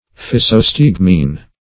Physostigmine \Phy`so*stig"mine\, n. (Chem.)